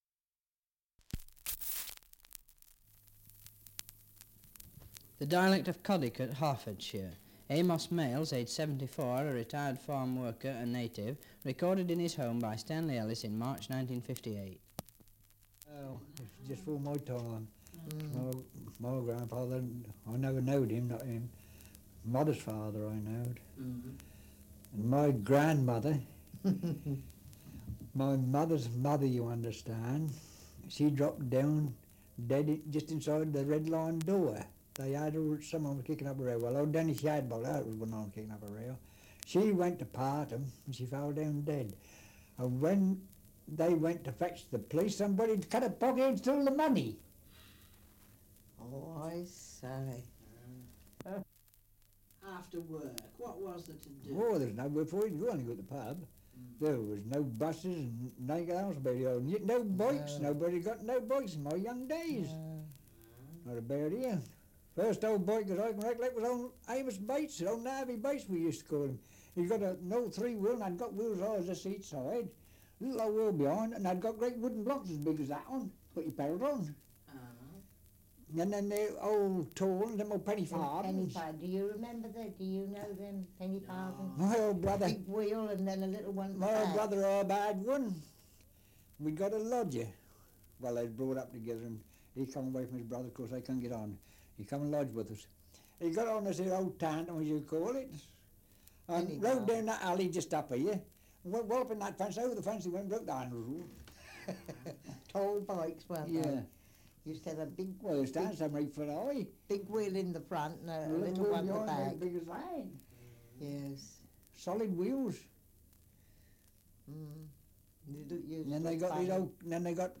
Survey of English Dialects recording in Codicote, Hertfordshire
78 r.p.m., cellulose nitrate on aluminium